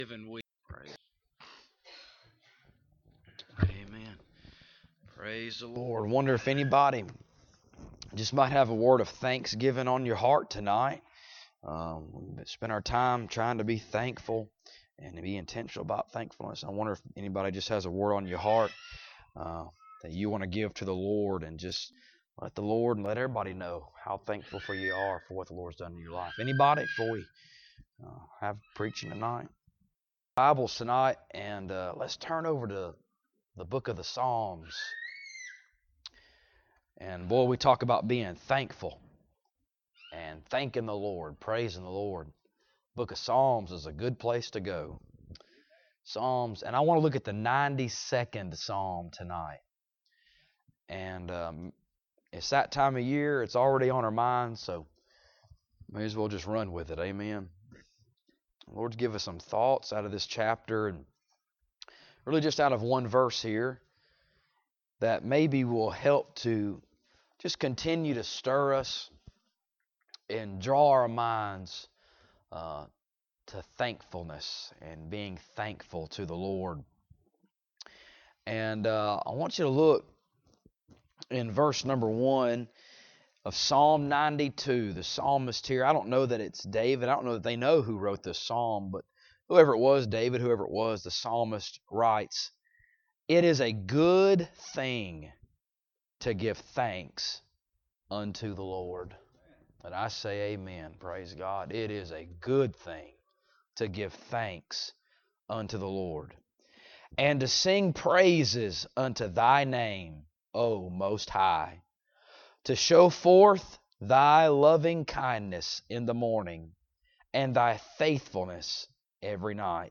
Passage: Psalms 92:1 Service Type: Wednesday Evening Topics